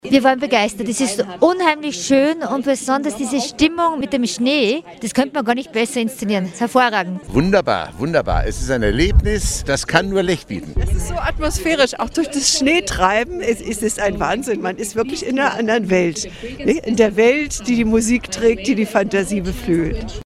Stimmen zu Spiel auf dem Schnee, Zuschauer